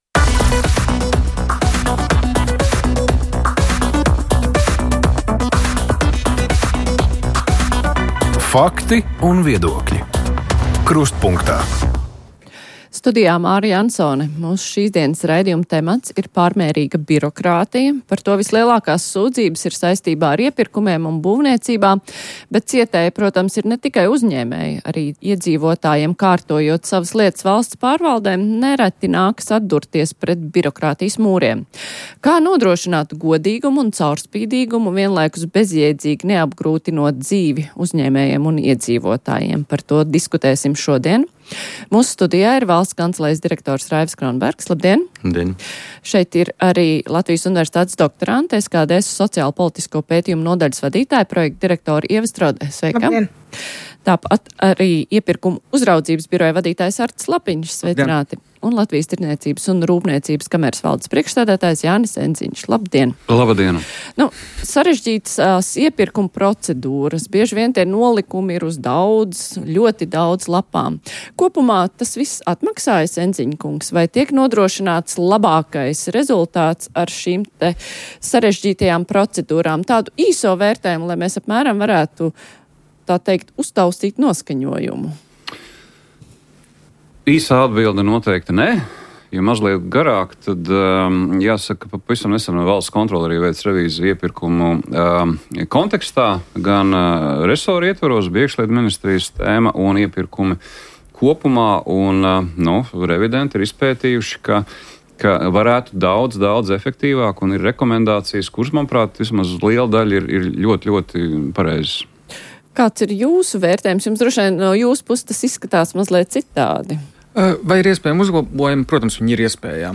Kā nodrošināt godīgumu un caurspīdīgumu, vienlaikus bezjēdzīgi neapgrūtinot dzīvi uzņēmējiem un iedzīvotājiem. Par to diskutējam raidījumā Krustpunktā.